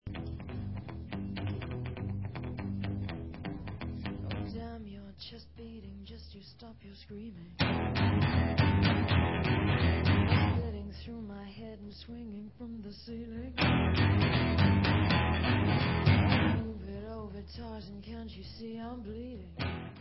sledovat novinky v oddělení Alternative Rock